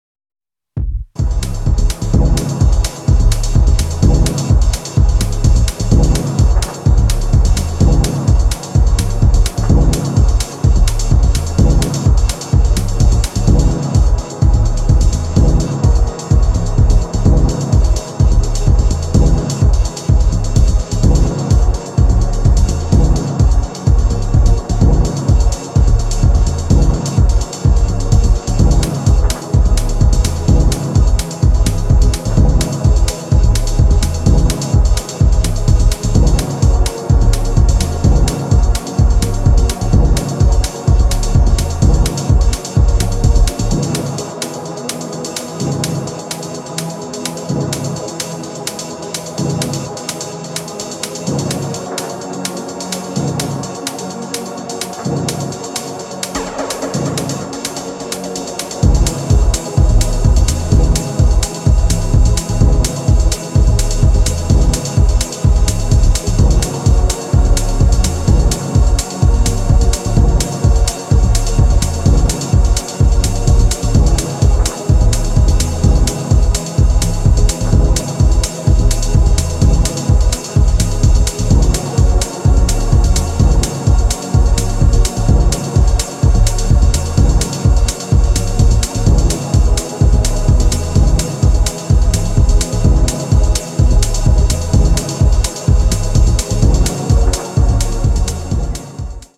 各曲アプローチを絶妙に変えながらもアトモスフェリックかつ幻想的なトーンを保ち続けていますね。